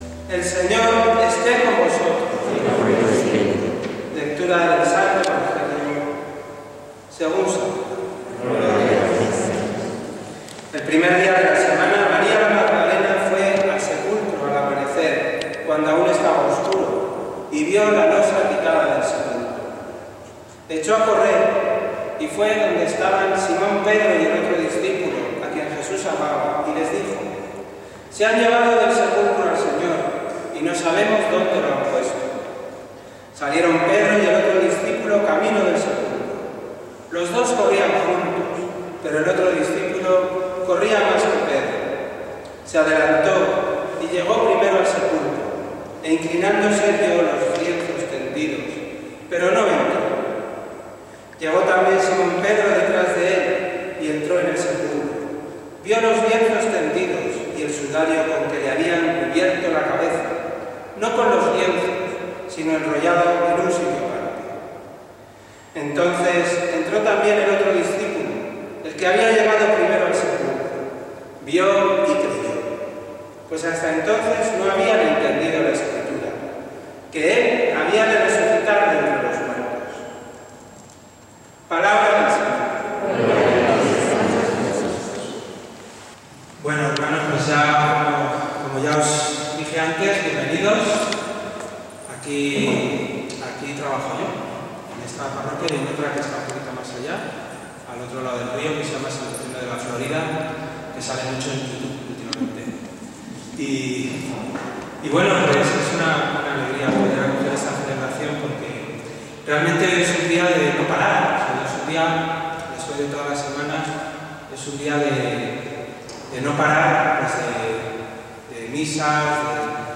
Misa de Resurrecci�n y Alabanza 04/04/2021